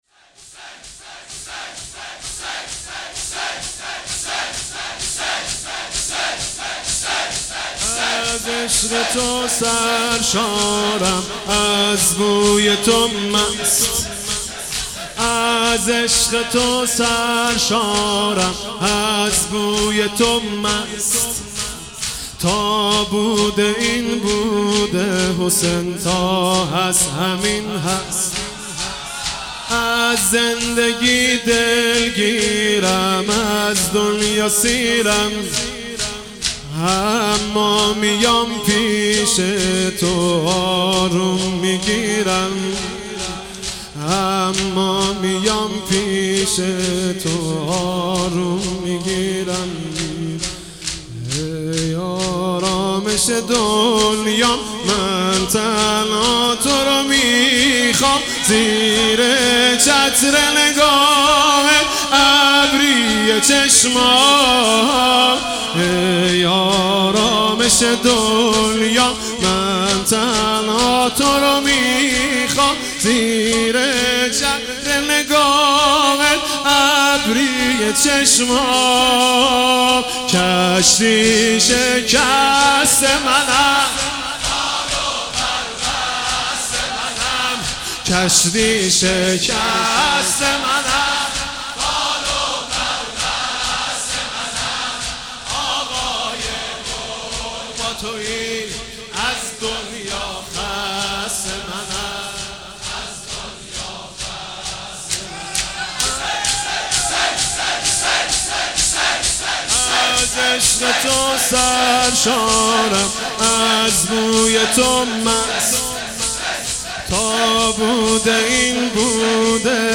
صوت مداحی های شب دوم محرم سال ۱۳۹۷ حاج محمود کریمی در رایه العباس علیه السلام را در ادامه می توانید مشاهده و دانلود نمایید.
روضه زمینه آوینی خوانی واحد دودمه شور